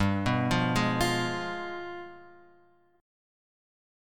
G 7th Flat 9th